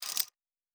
pgs/Assets/Audio/Sci-Fi Sounds/MISC/Metal Tools 03.wav at master
Metal Tools 03.wav